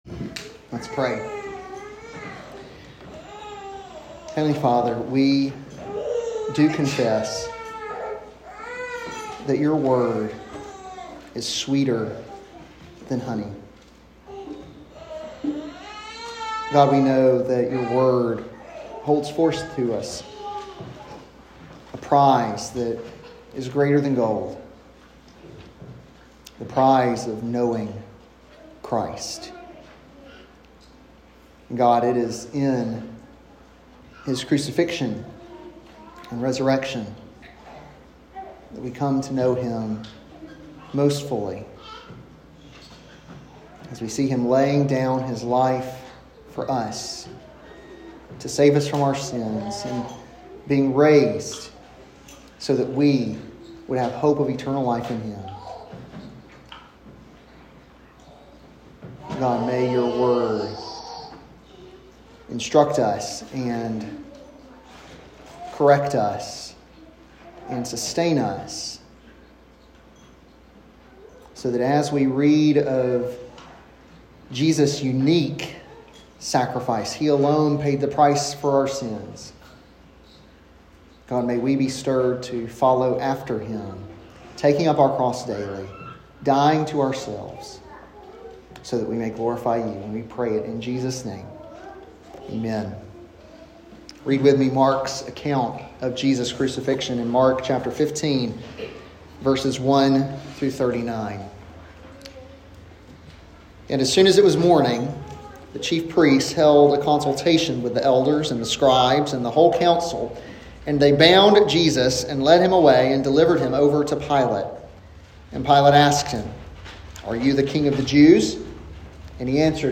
an expository sermon on Mark 15:1-39